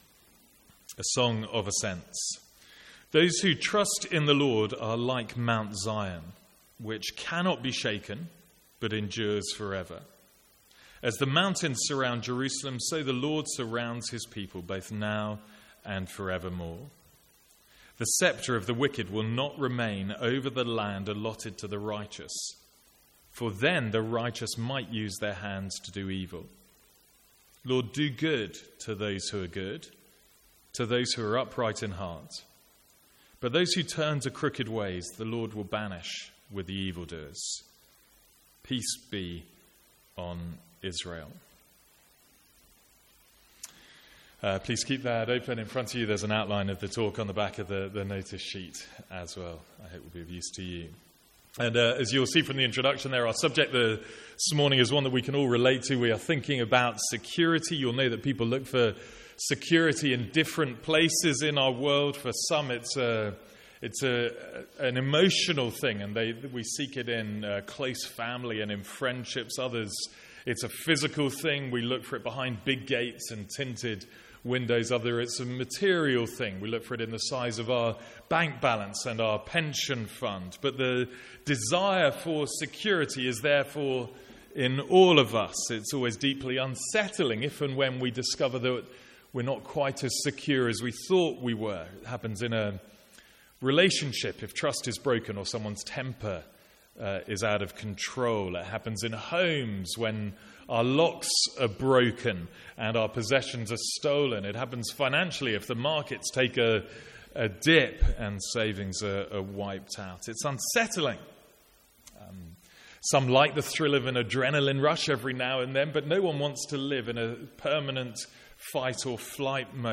Sermons | St Andrews Free Church
From the Sunday morning series in the Psalms.